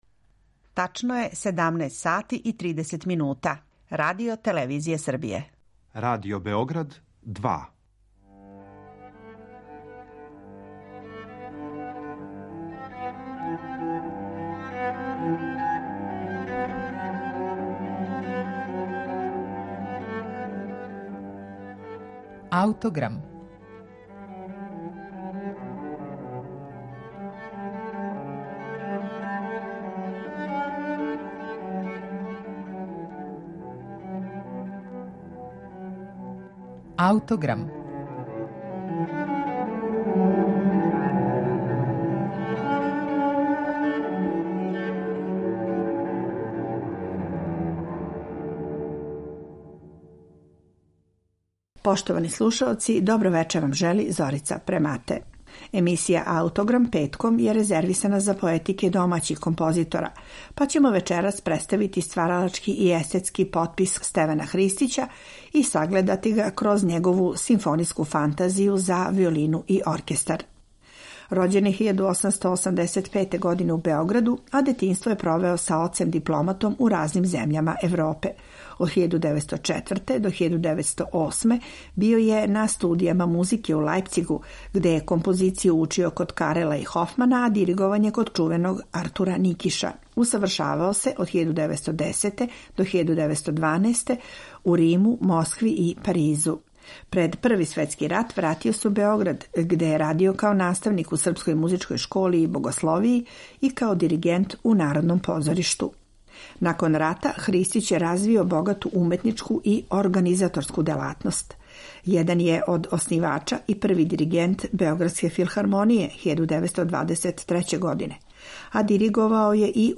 Симфонијска фантазија за виолину и оркестар, Стевана Христића